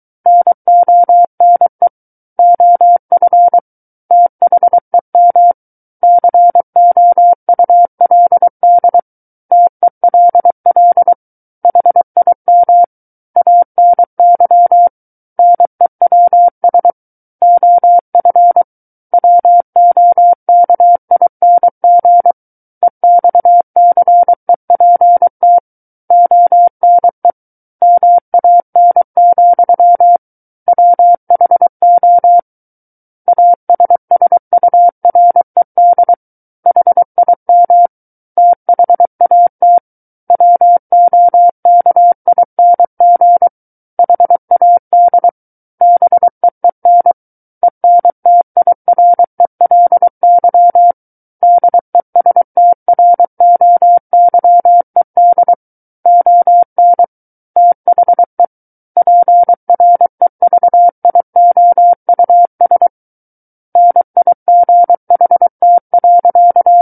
War of the Worlds - 14-Chapter 14 - 23 WPM